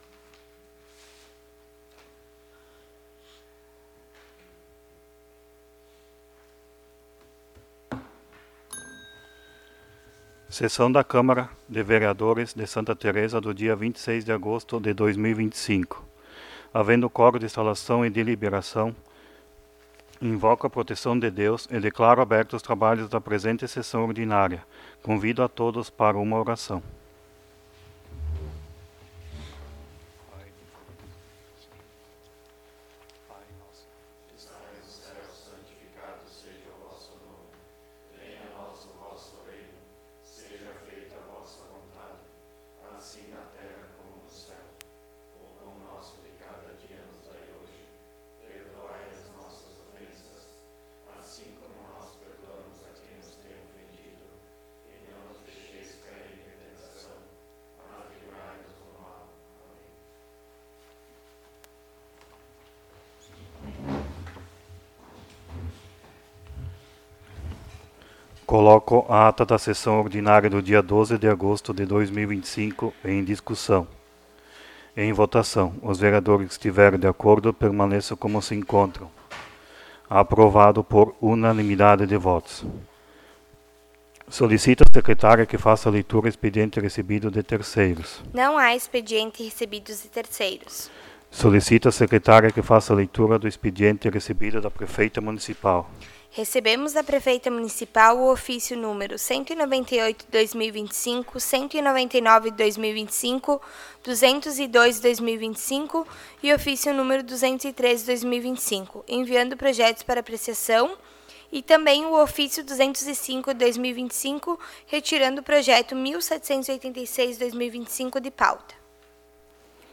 14° Sessão Ordinária de 2025